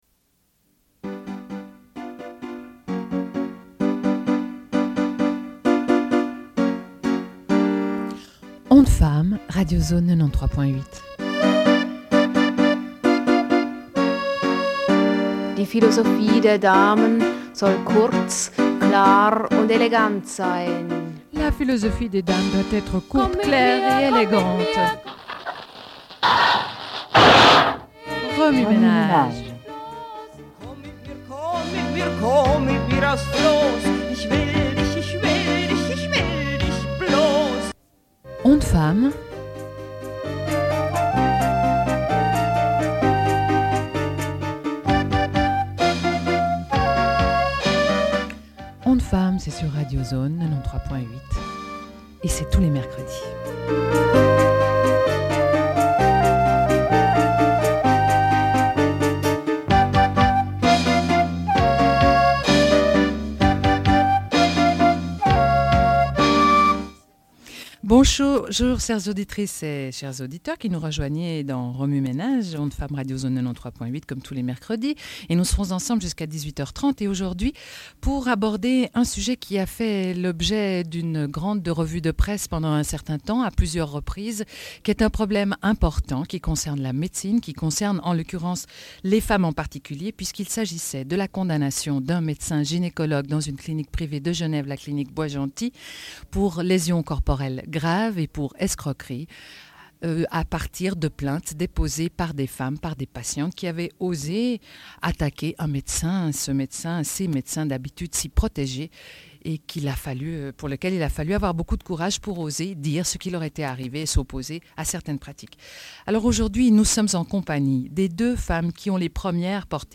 Une cassette audio, face A31:27